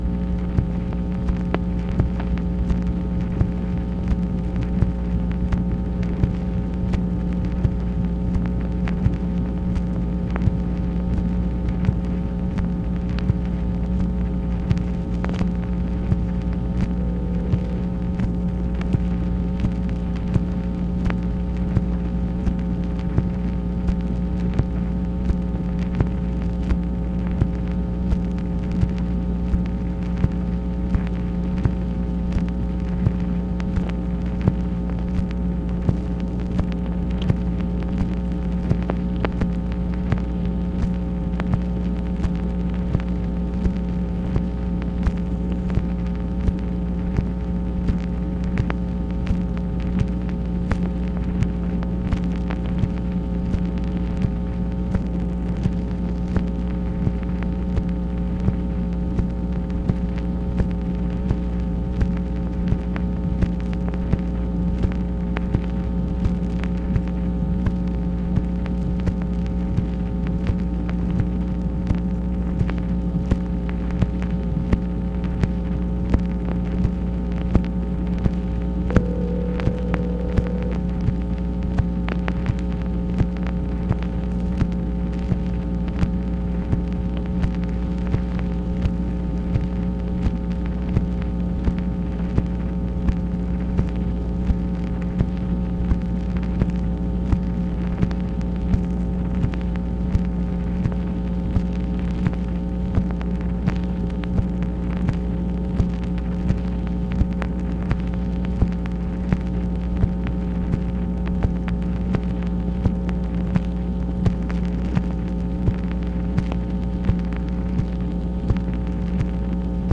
MACHINE NOISE, February 16, 1967
Secret White House Tapes | Lyndon B. Johnson Presidency